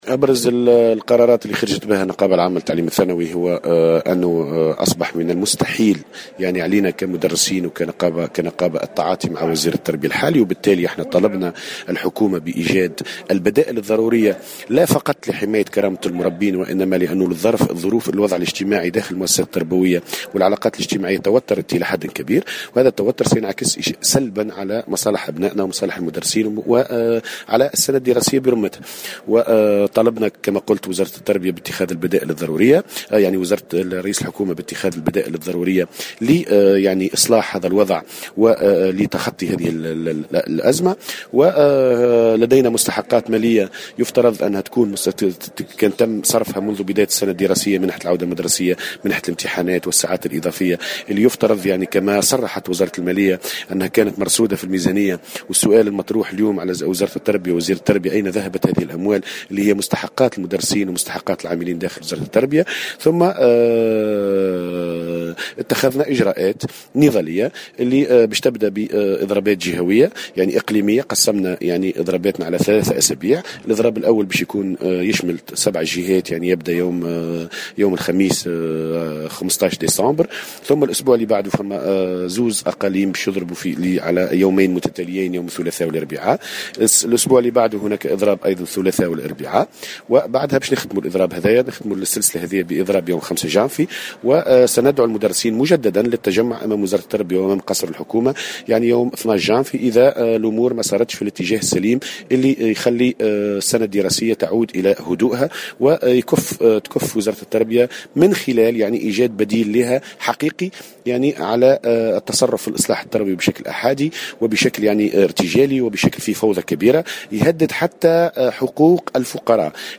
في تصريح لمراسلتنا في الحمامات